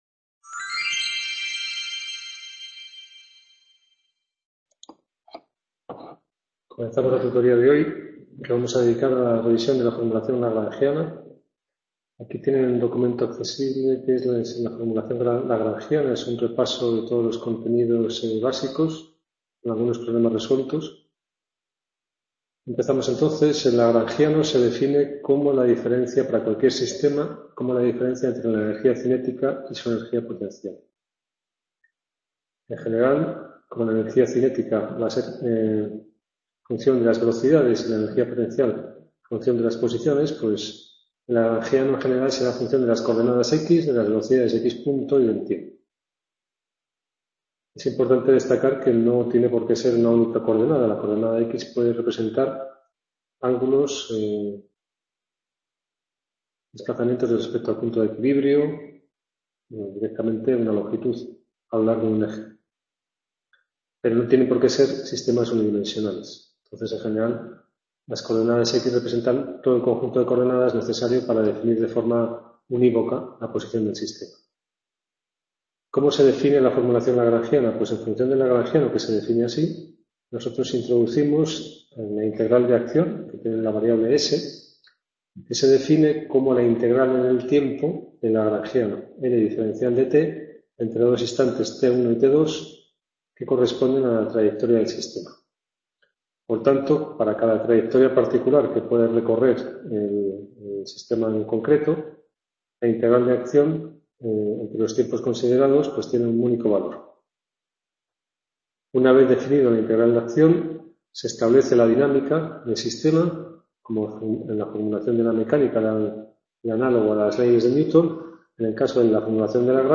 Tutoria Mecanica II - 20 Abril 2016 - Formulación de… | Repositorio Digital